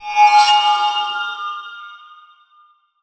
light_in_dark_spell_05.wav